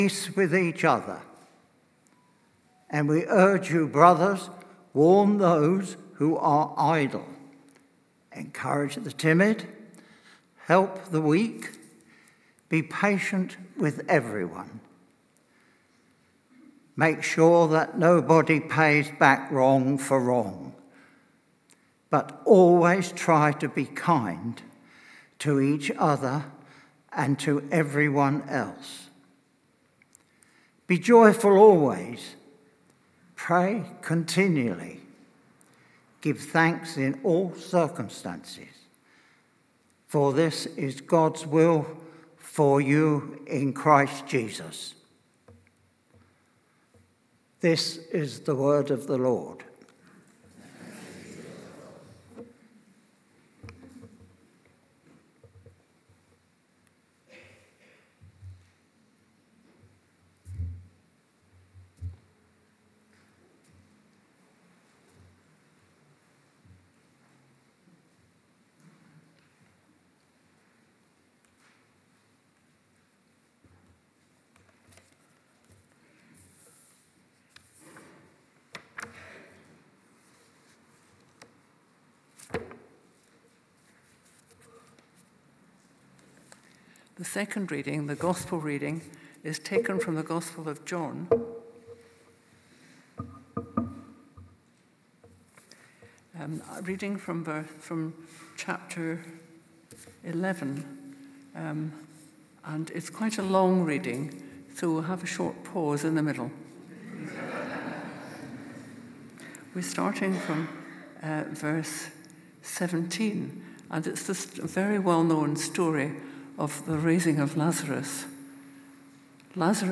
Emmanuel Church Sermons I Am the Resurrection and the Life Play Episode Pause Episode Mute/Unmute Episode Rewind 10 Seconds 1x Fast Forward 30 seconds 00:00 / 28:57 Subscribe Share RSS Feed Share Link Embed